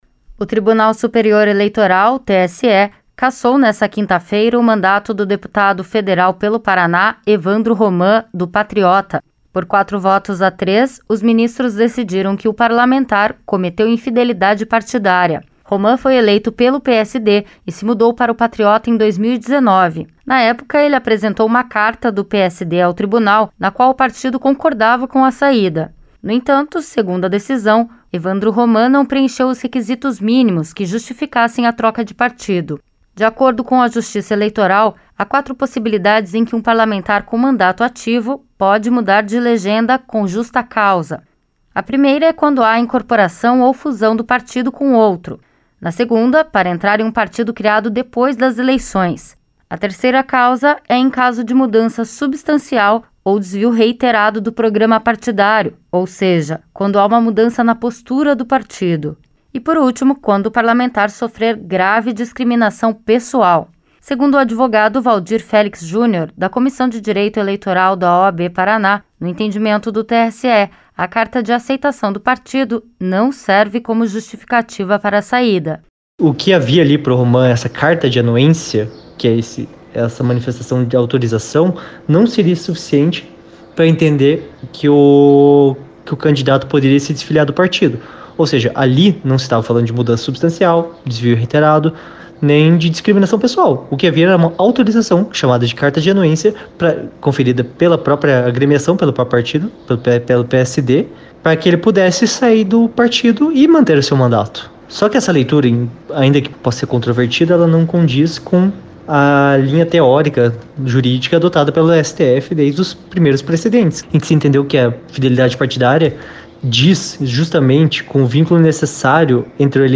Em votação apertada, o deputado federal Evandro Roman perdeu o cargo por infidelidade partidária. Os detalhes, na reportagem.